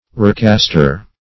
Roughcaster \Rough"cast`er\, n. One who roughcasts.